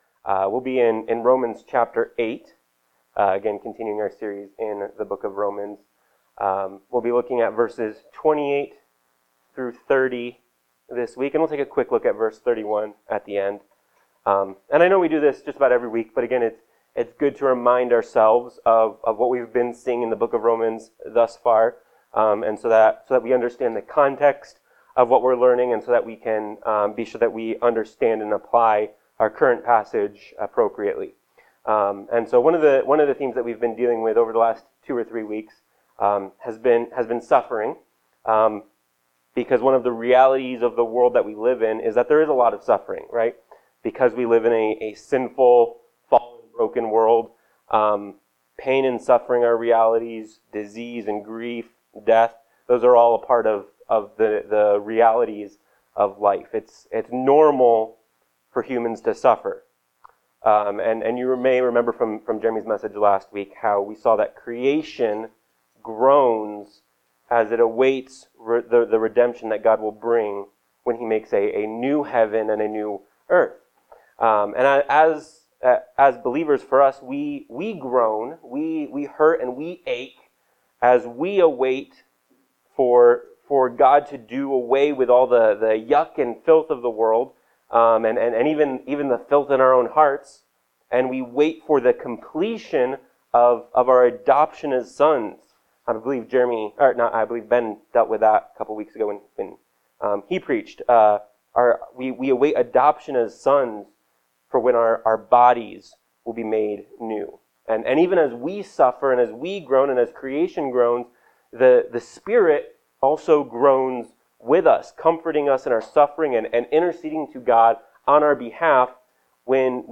Romans 8:28-30 Service Type: Sunday Morning Worship « Romans 8:22-27